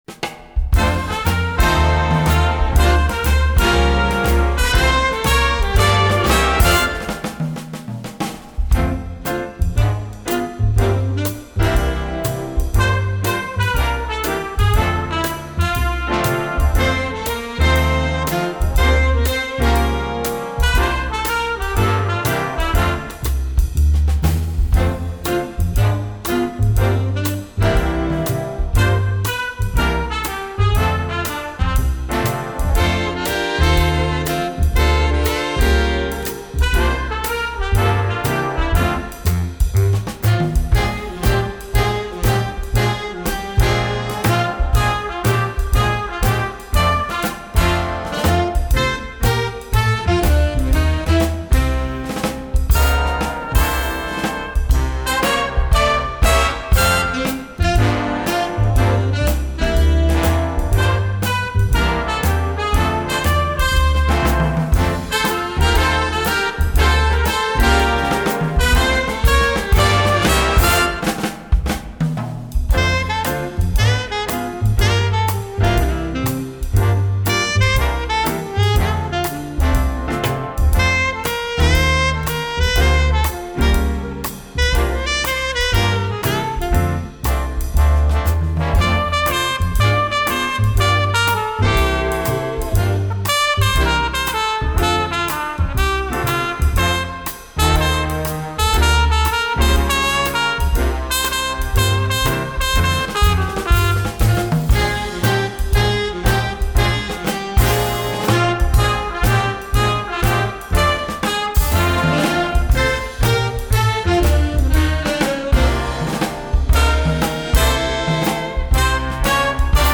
Répertoire pour Jazz band